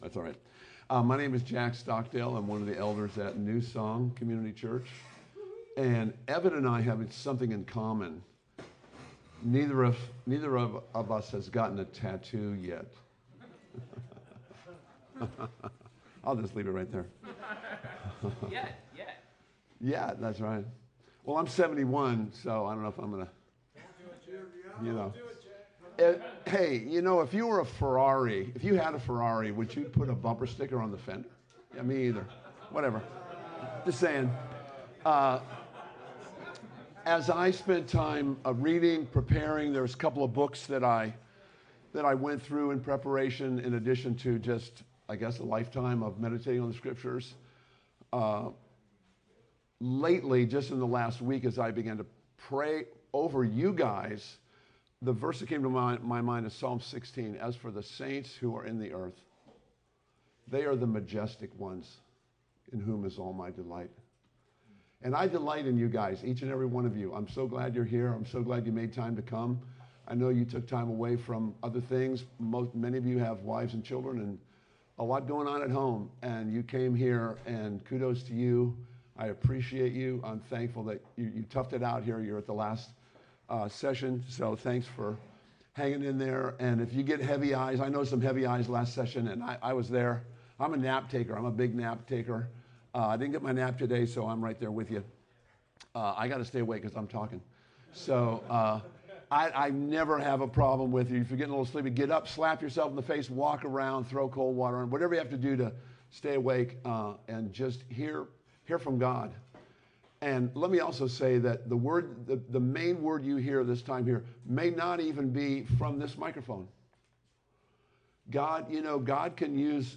Session 4 of the Faithful Masculinity men’s retreat